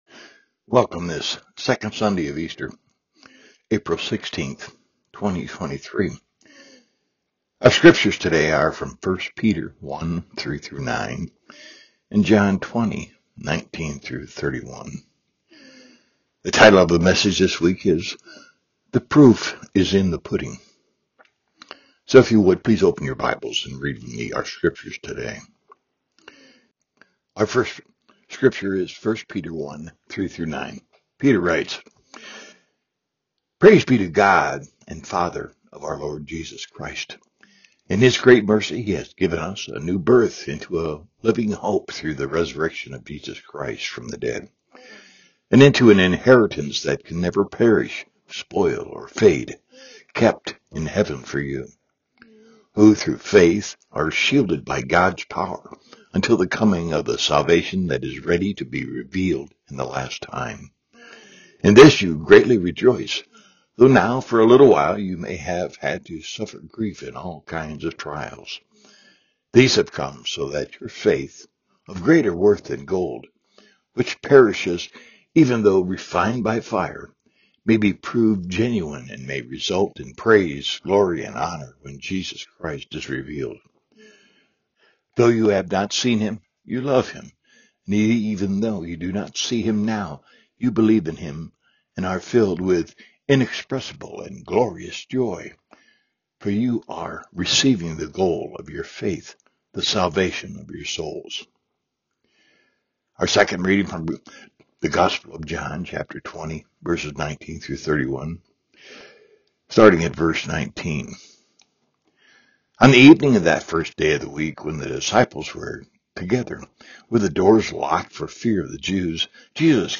Worship Service – April 16, 2023 « Franklin Hill Presbyterian Church